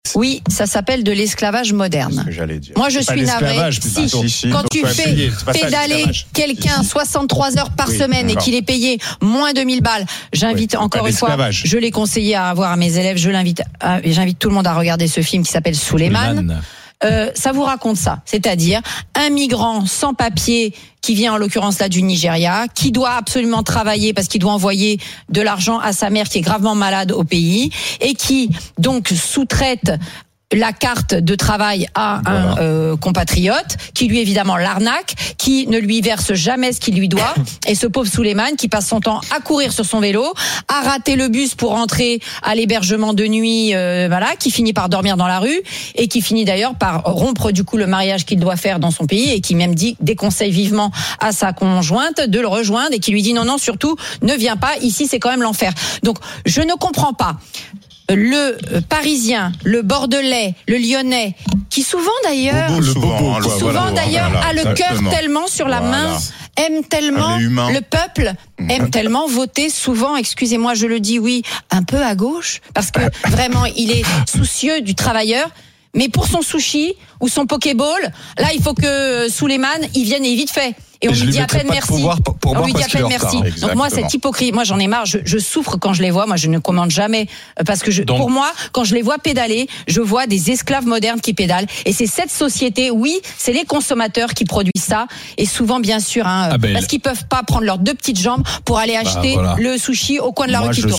Genres: News, News Commentary